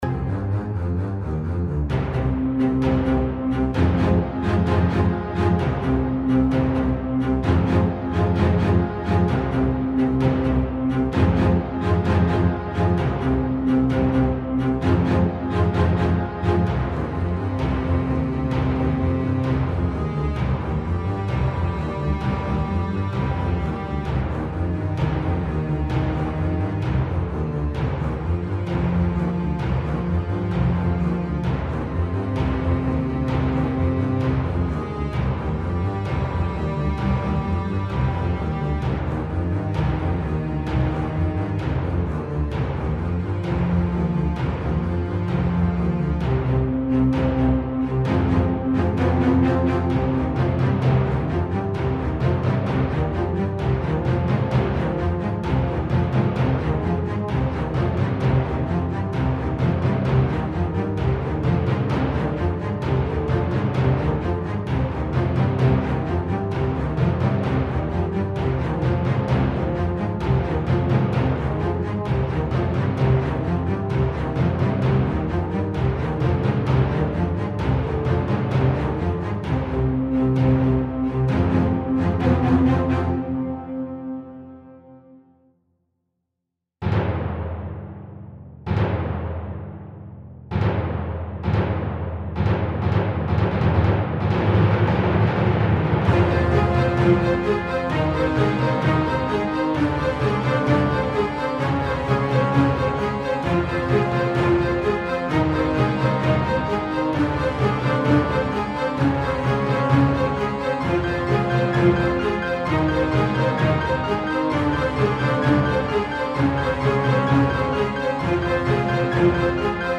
This is 2 songs in one, basically.
Low quality only.